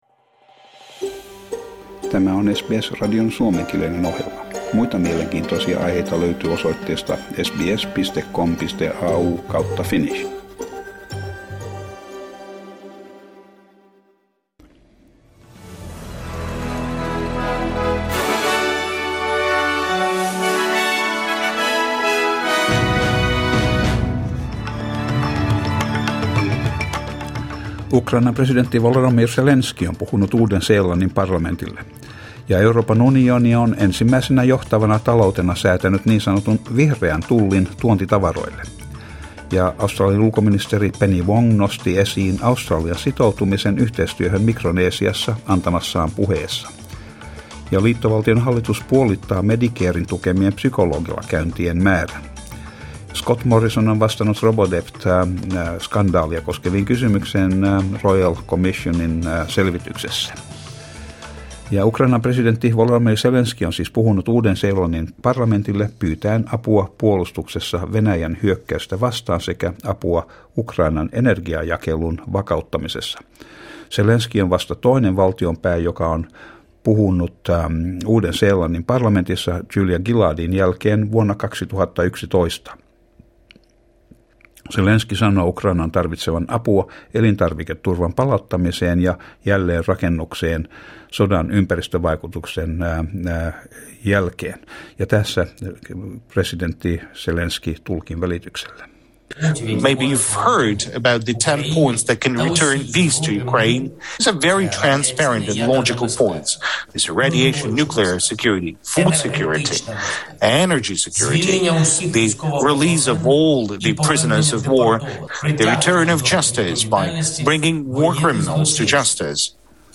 Uutiset 14.12.22